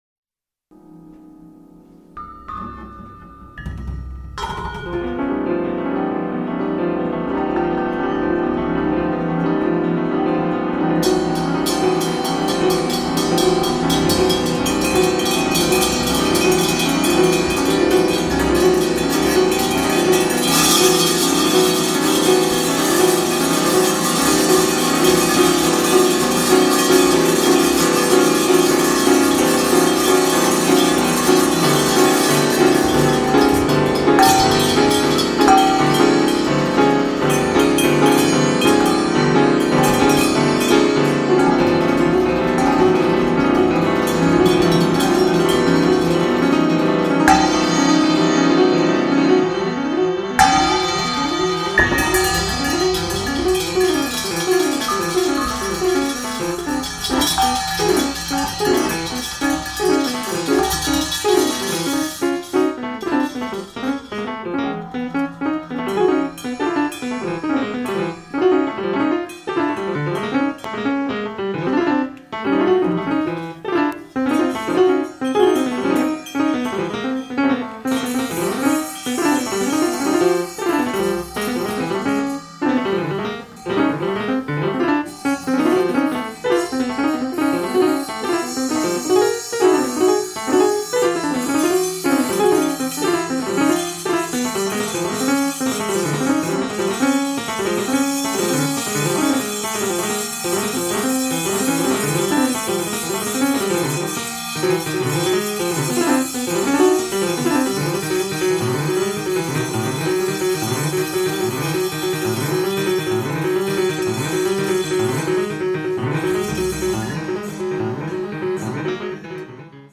French pianist